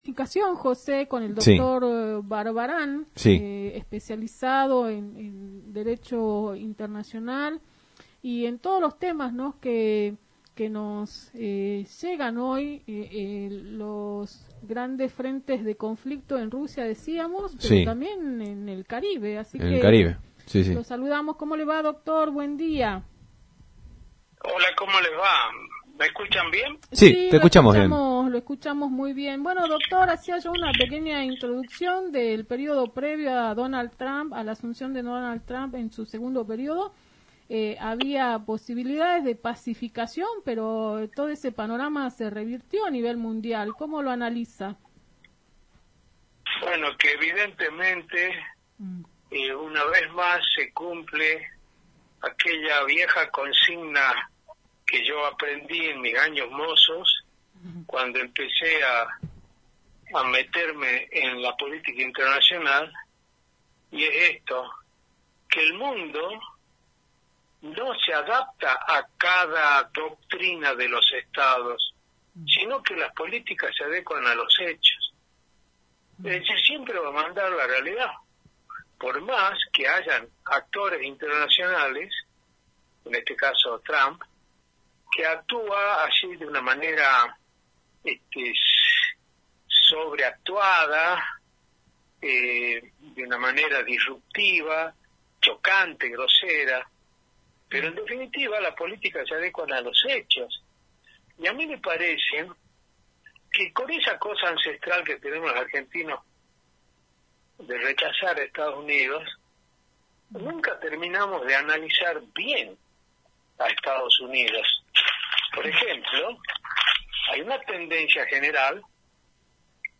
POLÍTICA INTERNACIONAL